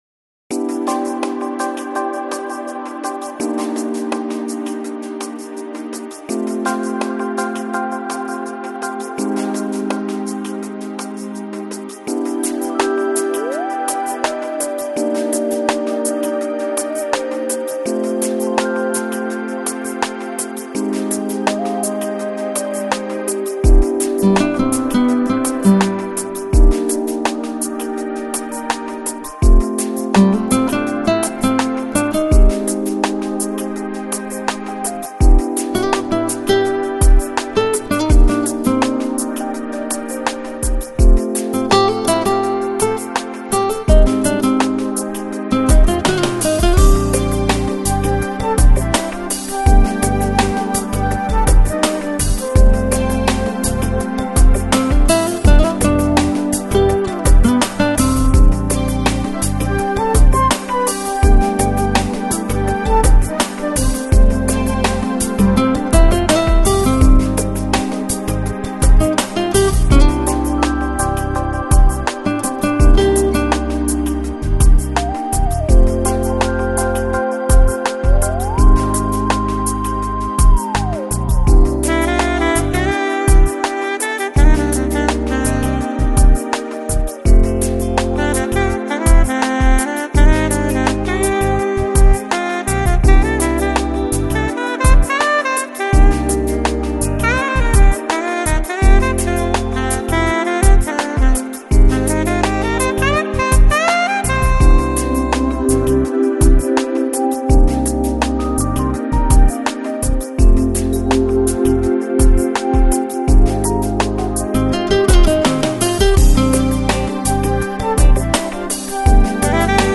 Electronic, Lounge, Chill Out, Balearic, Downtempo